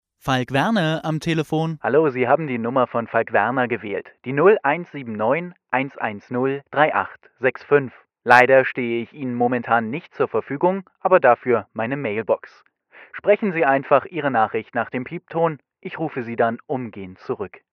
deutscher Profi - Sprecher für Firmenpräsentationen, Hörbuchproduktionen, Radio
Kein Dialekt
Sprechprobe: Sonstiges (Muttersprache):
german voice over talent